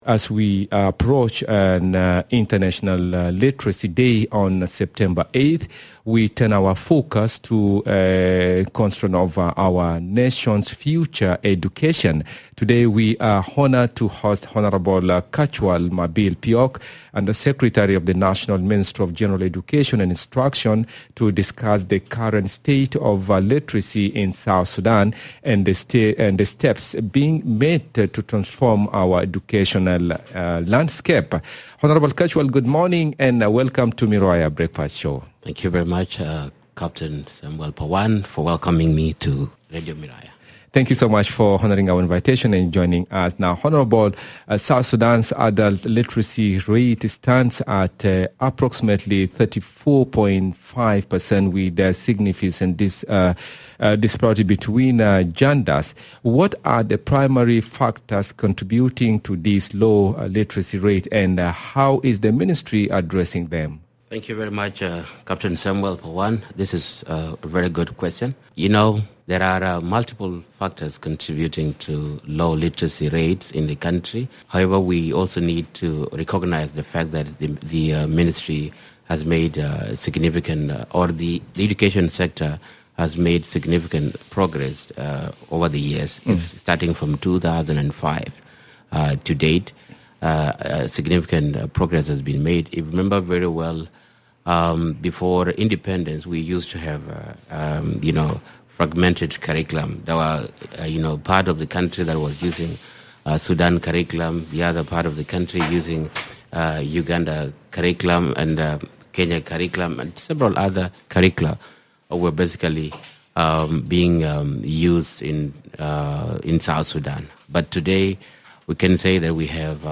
In this edition, we are joined by Kachuol Mabil Piok, Undersecretary of the National Ministry of General Education and Instruction. He shares insights on the current state of literacy in South Sudan and the steps being taken to transform the educational landscape.